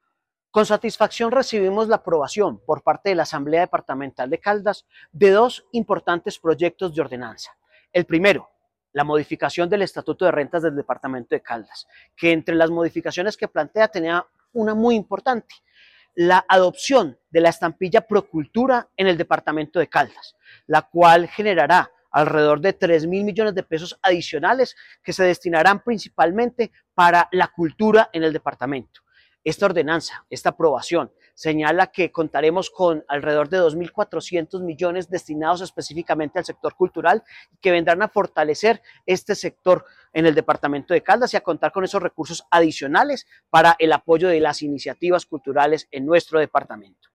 Jhon Alexánder Alzate Quiceno, secretario de Hacienda de Caldas